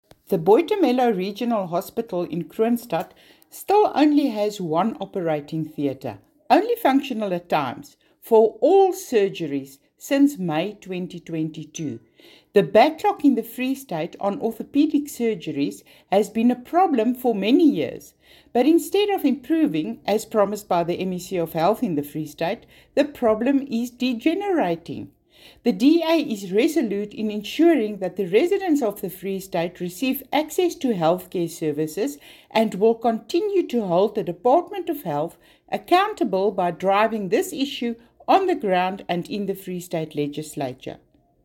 Afrikaans soundbites by Mariette Pittaway MPL and